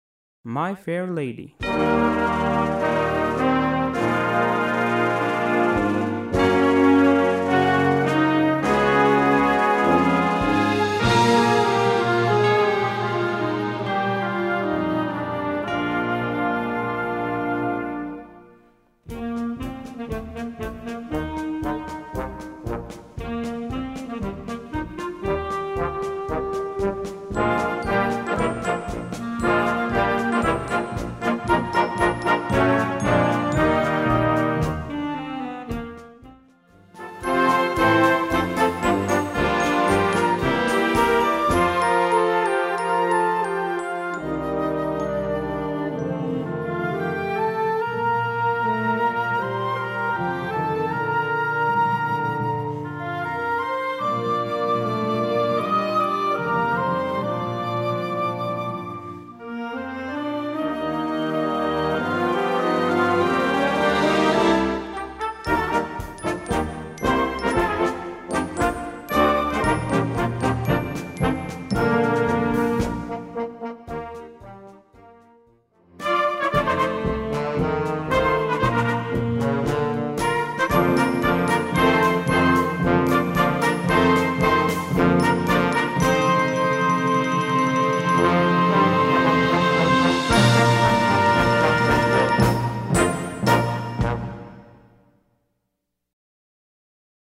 Gattung: Selection für Jugendblasorchester
Besetzung: Blasorchester